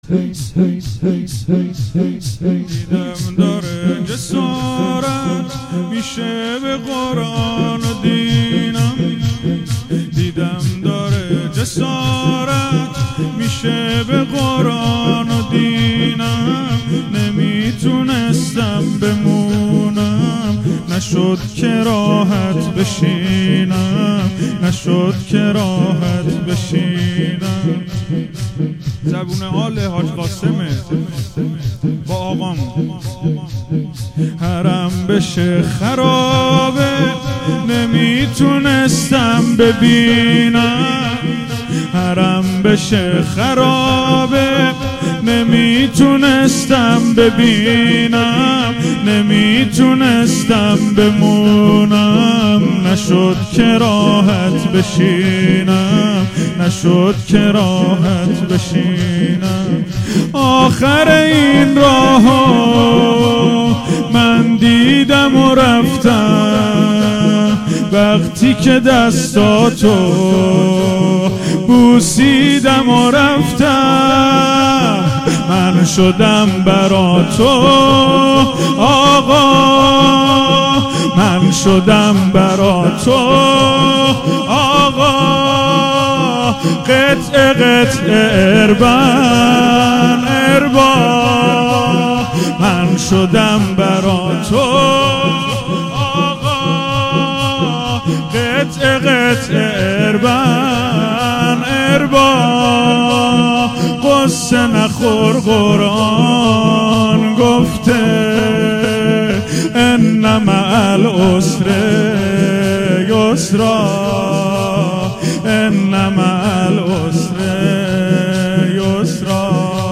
مداحی شور
مراسم روضه هفتگی 24بهمن1398